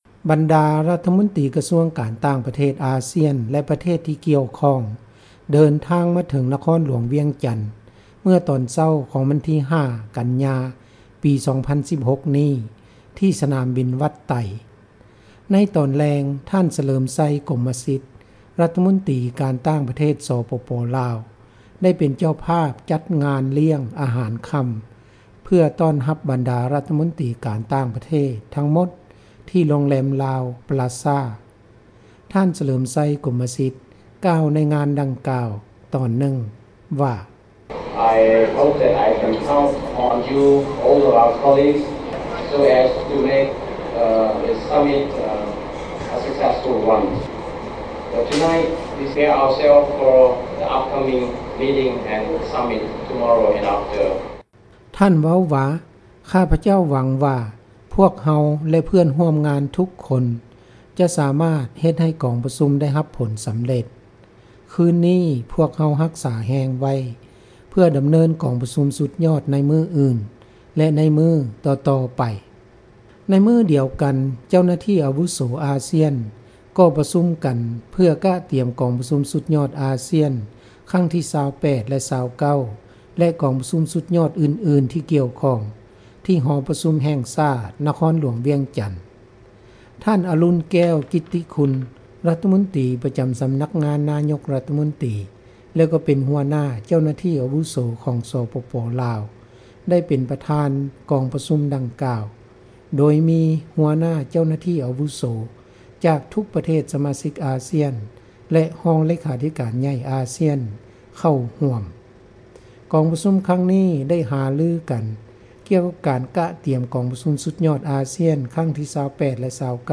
ຣາຍງານ ຈາກນະຄອນຫລວງວຽງຈັນ.